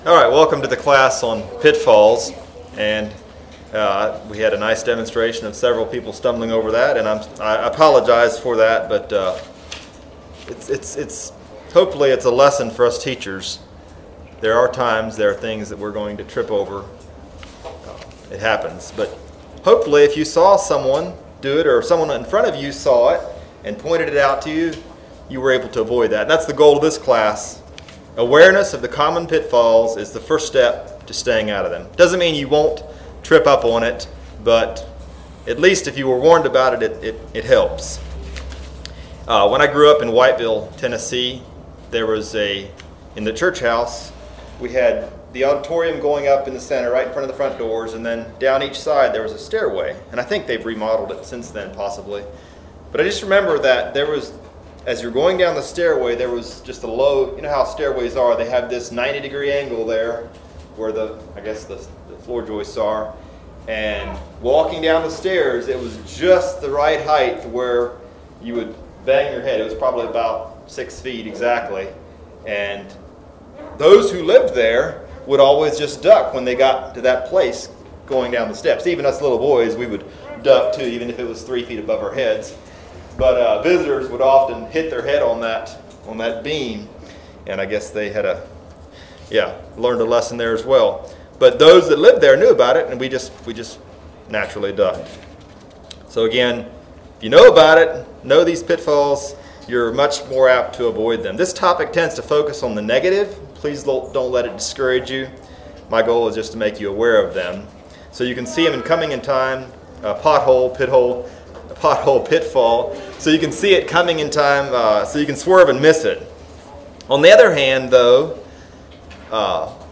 2025 Midwest Teachers Week 2025 Recordings Pitfalls Audio 00:00